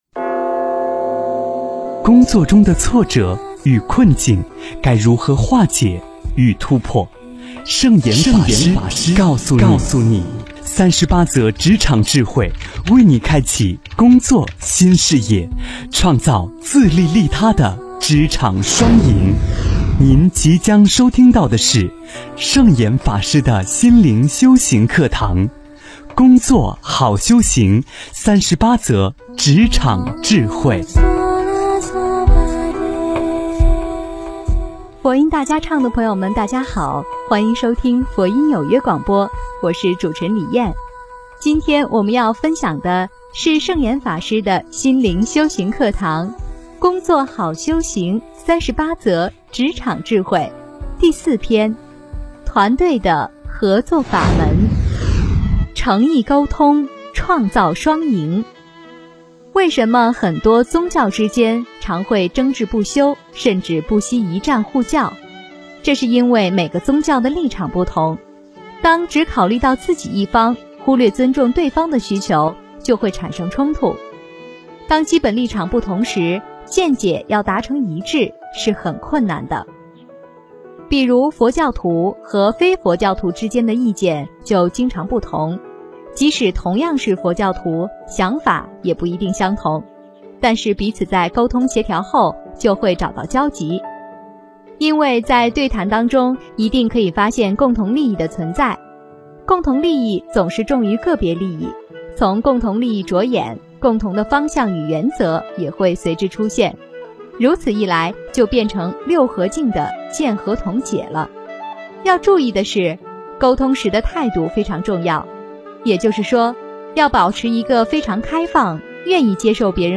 职场34诚意沟通创造双赢--佛音大家唱 真言 职场34诚意沟通创造双赢--佛音大家唱 点我： 标签: 佛音 真言 佛教音乐 返回列表 上一篇： 职场31口和无诤--佛音大家唱 下一篇： 职场37戒和同修--佛音大家唱 相关文章 貧僧有話9說：我怎样走上国际的道路--释星云 貧僧有話9說：我怎样走上国际的道路--释星云...